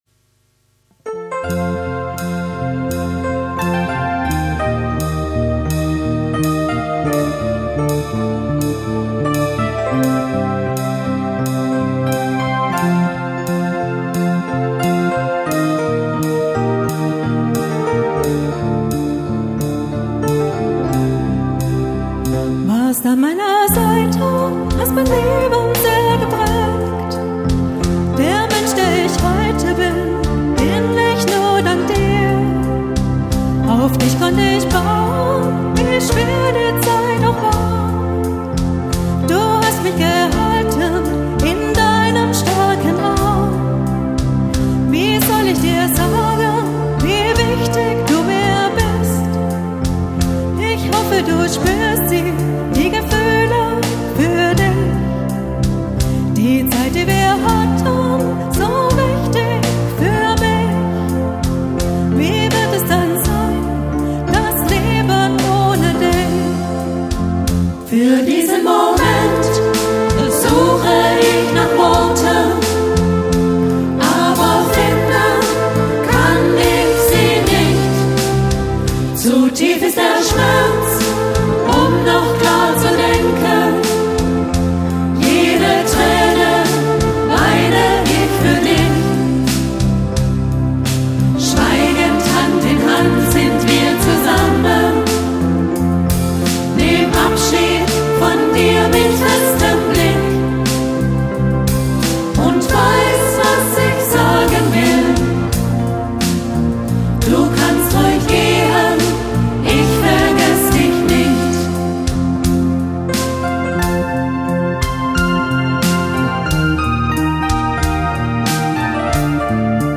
Unser Gebet in die Nacht konnte aber trotzem wieder stattfinden.